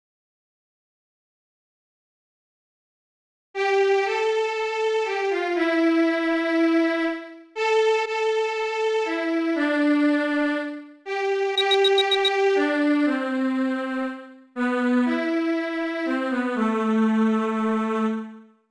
我凭记忆写了歌谱（不全，只有后半部分），然后播放出来。